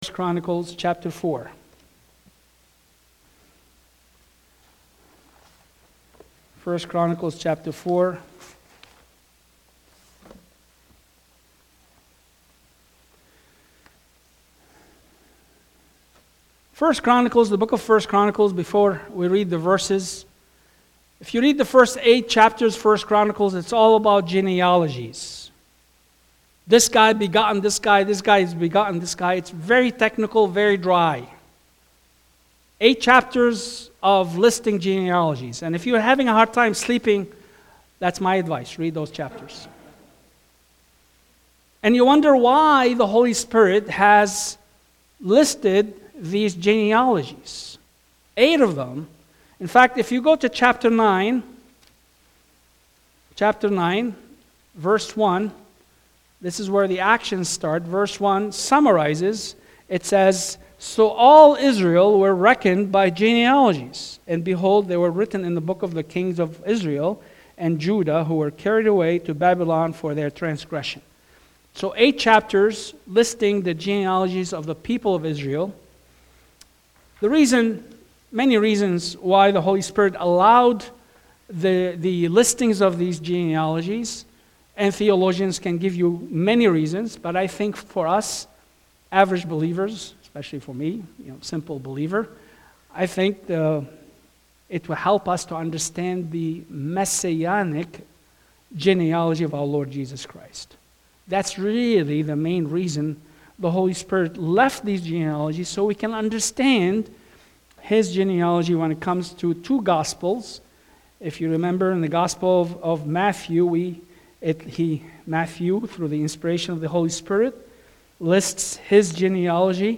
Passage: 1 Chronicles 4:9-10 Service Type: Sunday AM « May 25